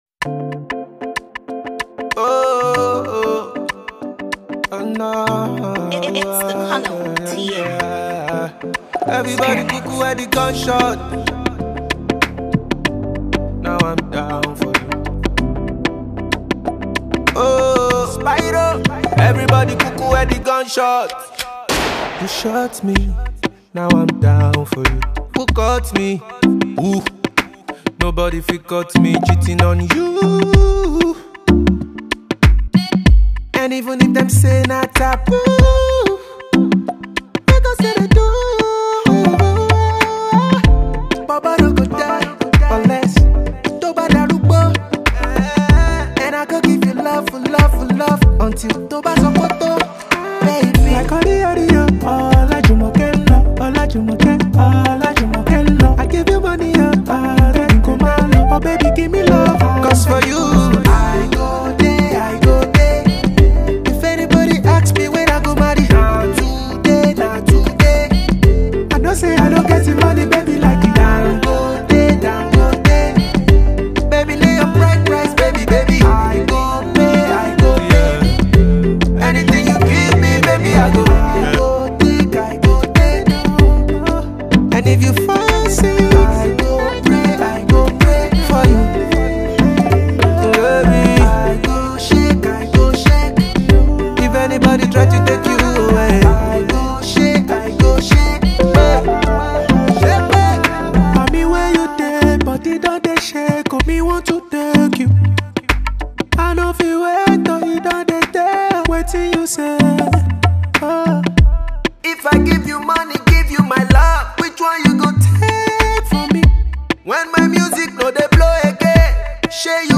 sonorous vocals
a brilliant Afro-pop jam